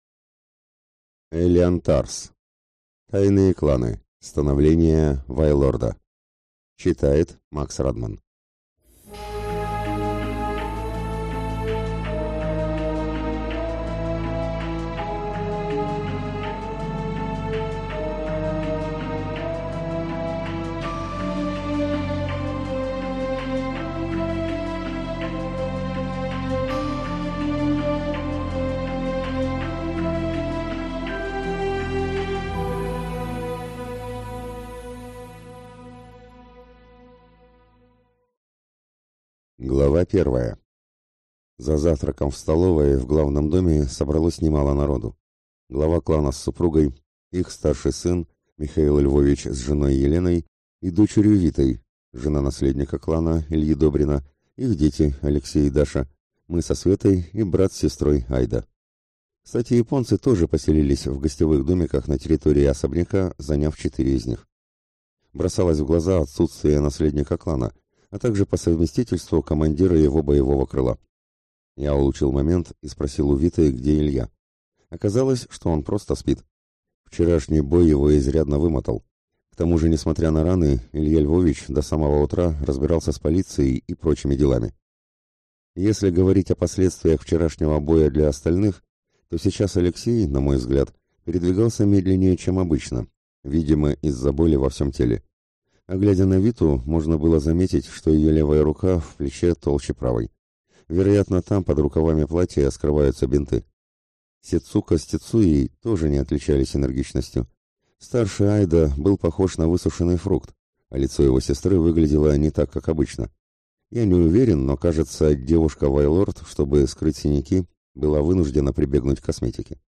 Аудиокнига Становление вайлорда | Библиотека аудиокниг